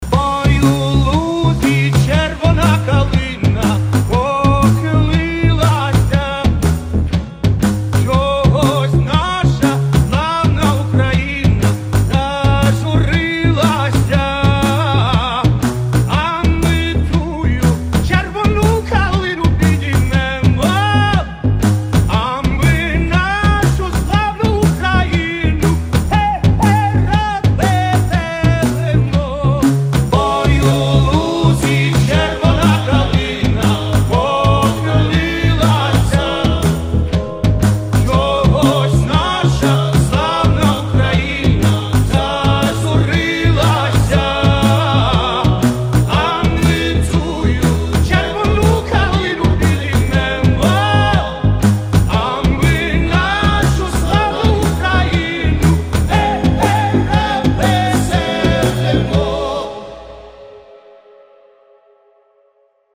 • Качество: 320, Stereo
мужской голос
Electronic
спокойные